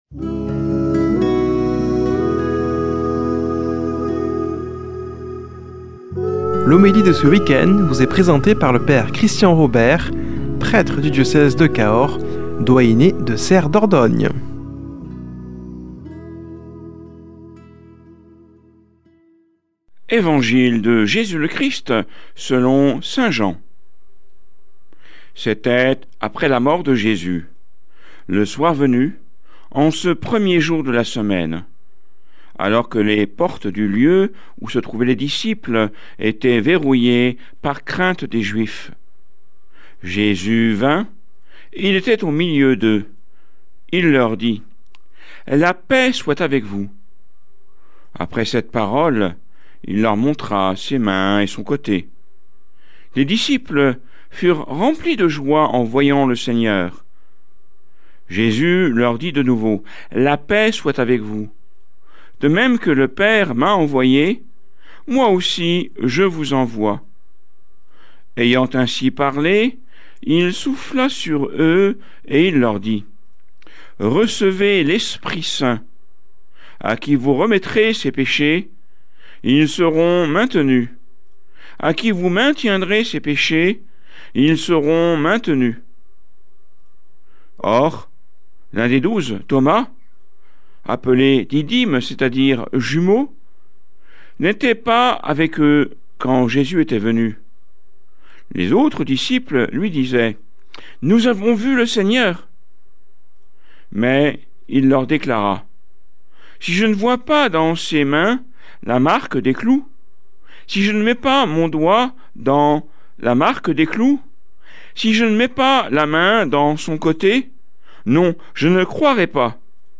Homélie du 11 avr.